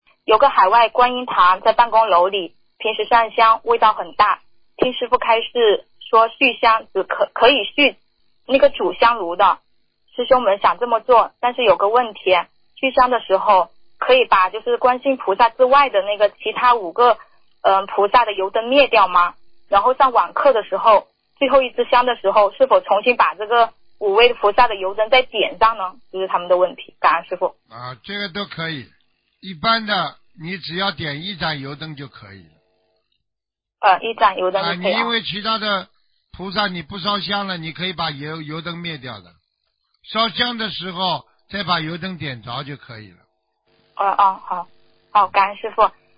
▶ 语 音 朗 读